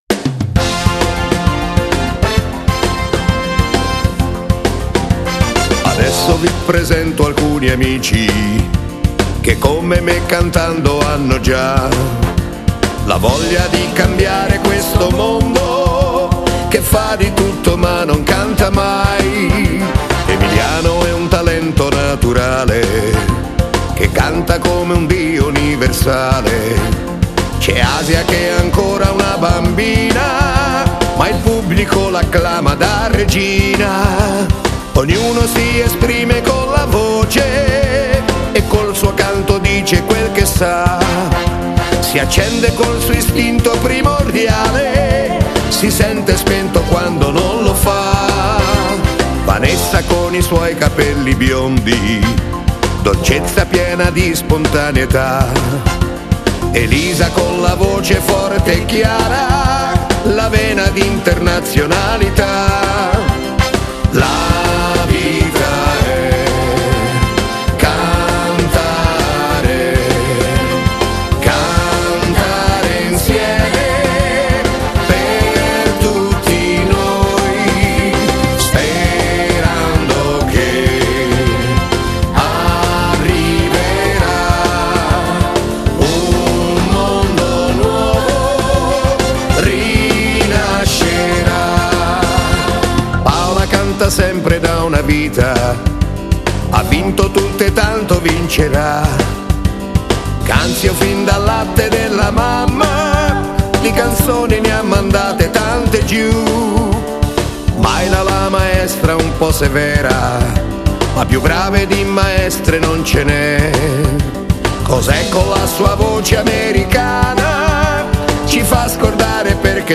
Genere: Hully gully